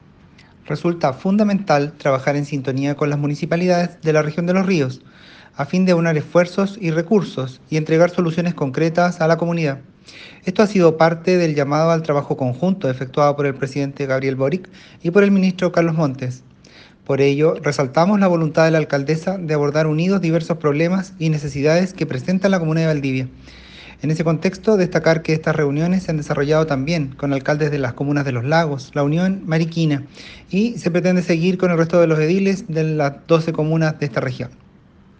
seremi-en-entrevista-con-alcaldesa.ogg